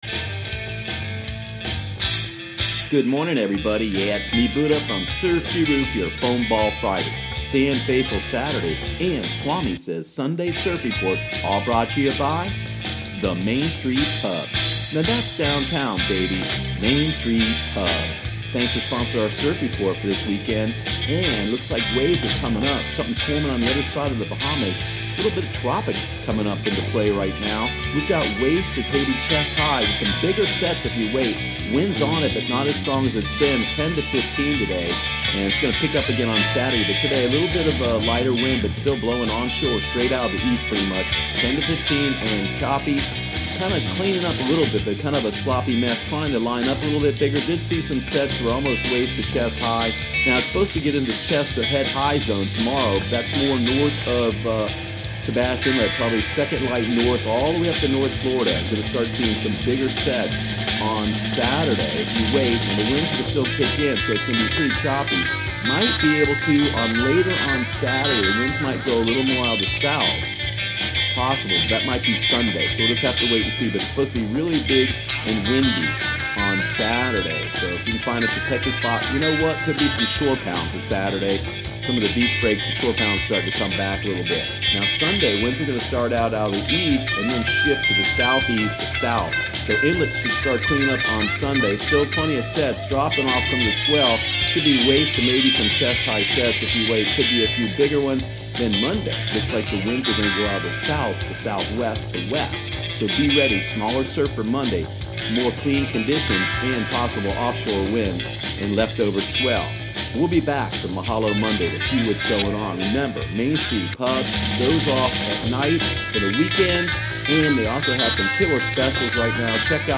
Surf Guru Surf Report and Forecast 05/04/2018 Audio surf report and surf forecast on May 04 for Central Florida and the Southeast.